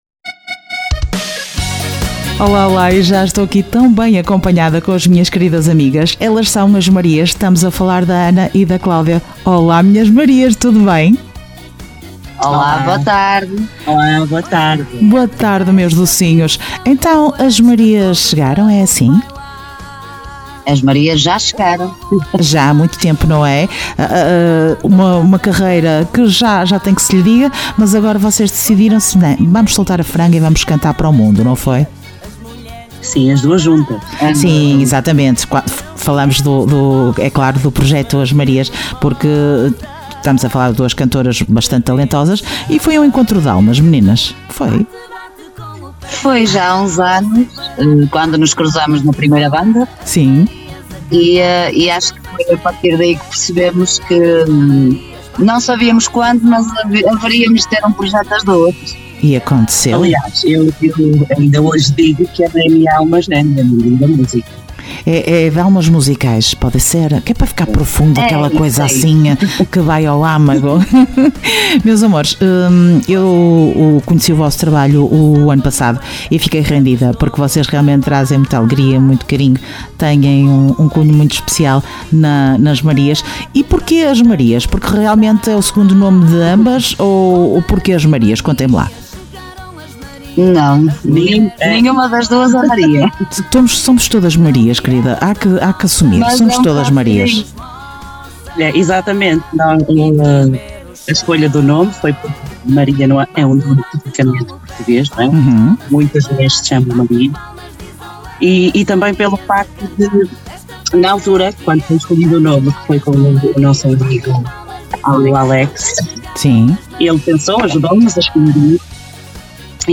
Entrevista As Marias dia 27 de Junho